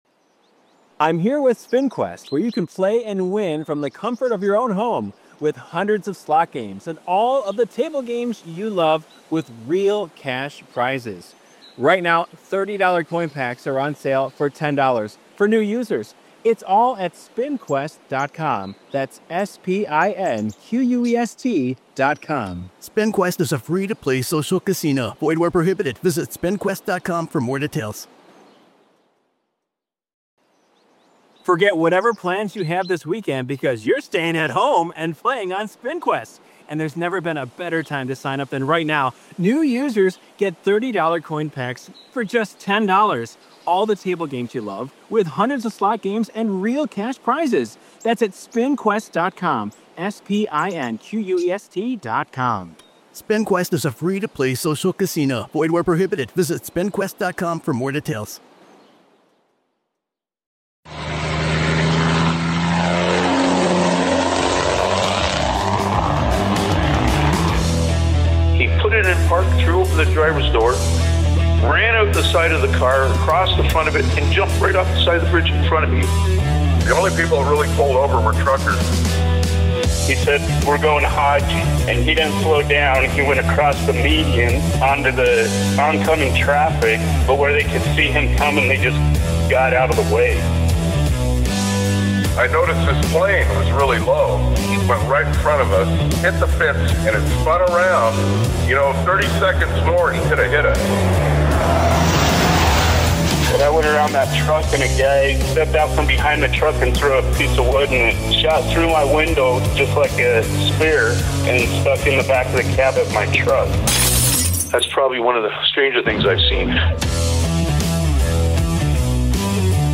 a driver for many years, joins the show to talk about his travels all over the United States.